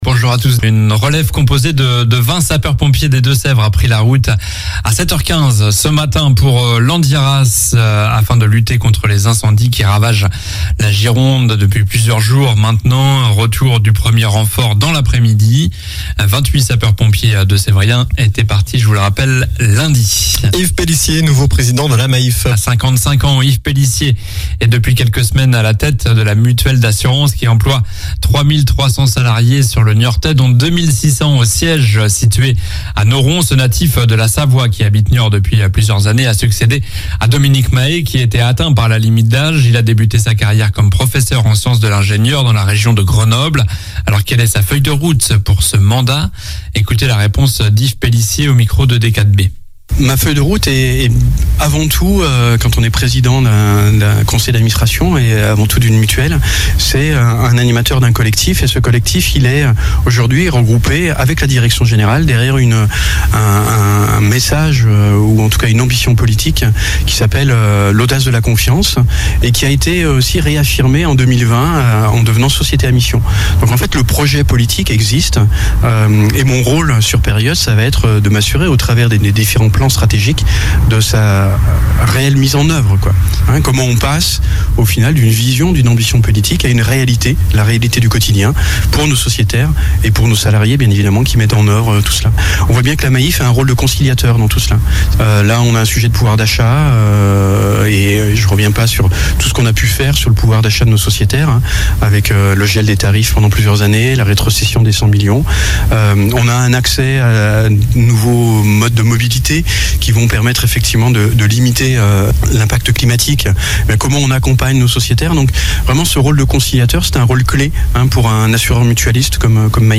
Journal du jeudi 21 juillet (matin)